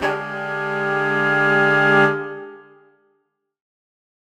Index of /musicradar/undercover-samples/Horn Swells/D
UC_HornSwell_Dminb5.wav